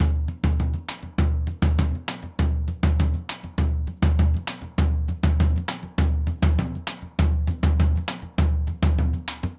Surdo Baion 100_2.wav